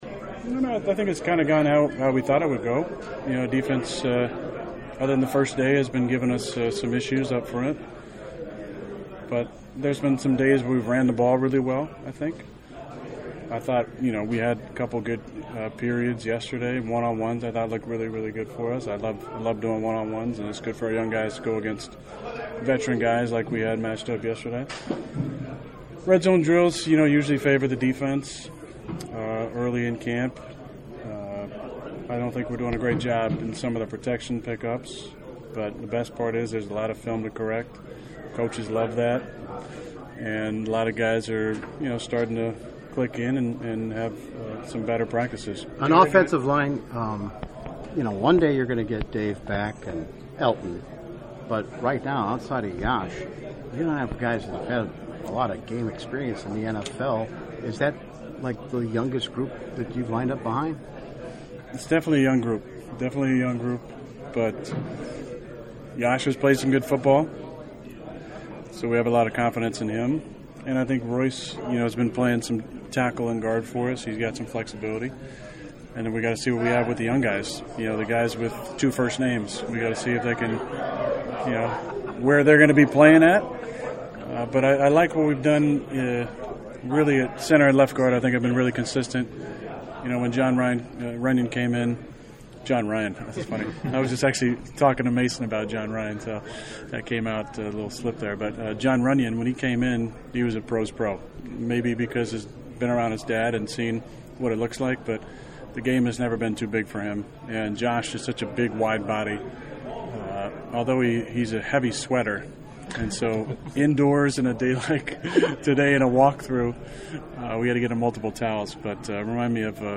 He held his weekly media session in front of his locker after practice and the 20 minute visit was lively. Rodgers talked about where the offense is overall one week into camp, the revolving door of offensive linemen in front of him and how they might not be able to wait for his new collection of pass catchers to develop a connection.